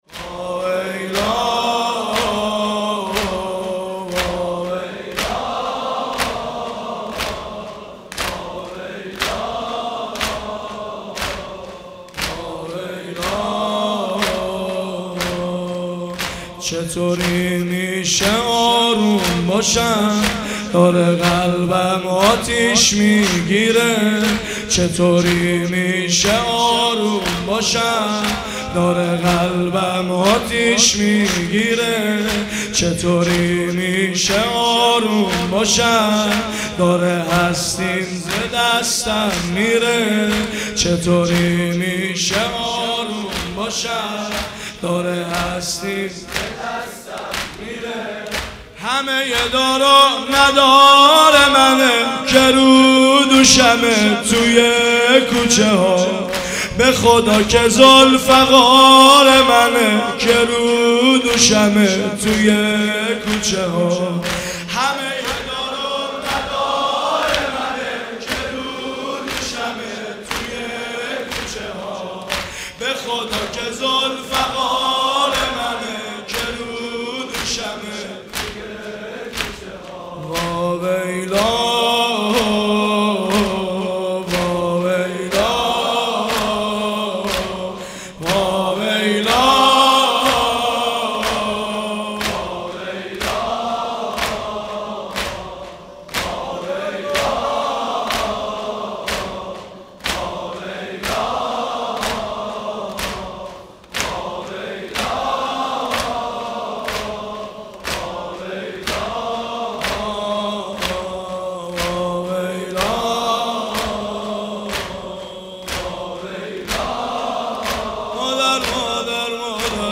• فاطمیه 91
• مداحی